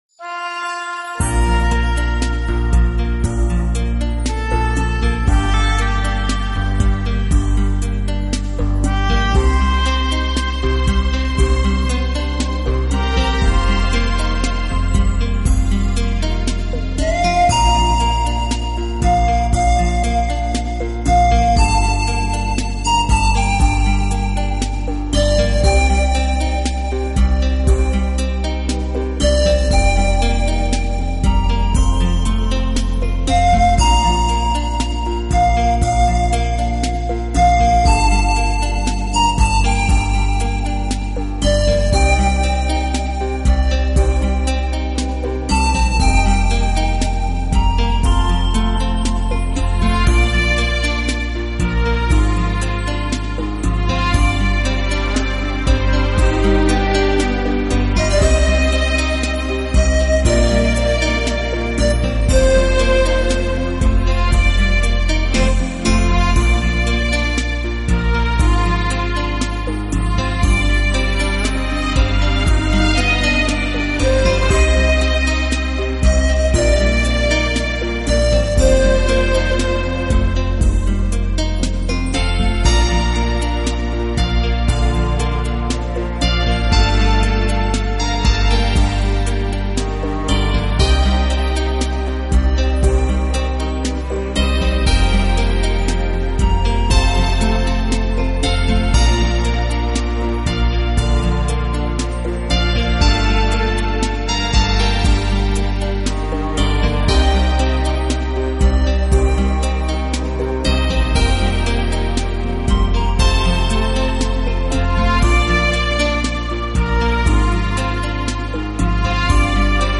【纯音乐】
来自瑞士的休闲音乐
以如梦似幻的清新自然音乐，著称乐坛。
那轻灵脱俗的旋律将引导着您远离尘世的喧嚣，亲近浩瀚的大自然。